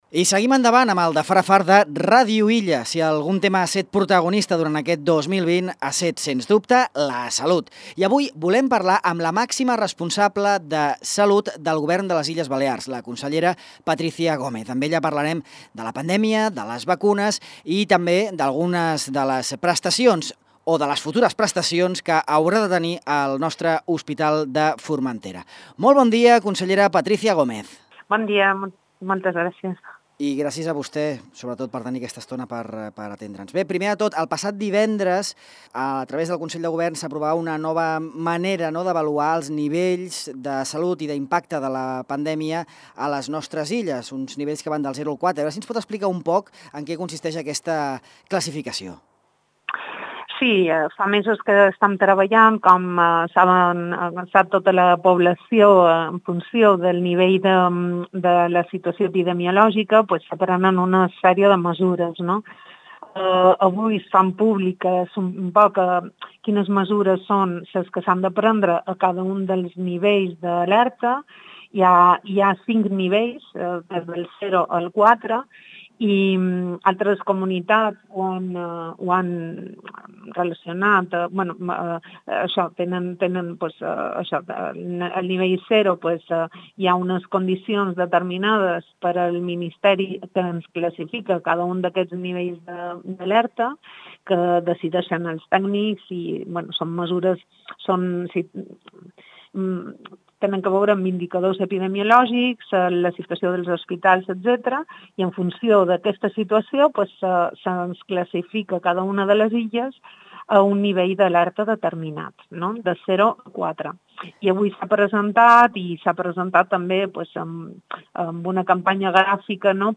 Patricia Gómez, consellera balear de Salut, explica a Ràdio Illa els cinc nous nivells d’alerta pandèmica a les Illes, així com l’anunci del Govern d’oferir PCR gratuïtes per als residents que tornin a la comunitat durant el pont de la Constitució. Gómez també parla de les necessitats pel que fa als recursos materials i humans per fer complir la proposta aprovada al Parlament perquè la diàlisi torni a l’Hospital de Formentera. A més, la consellera ha abordat el funcionament del TAC i el desplegament a Formentera del servei del 061 durant tot l’any.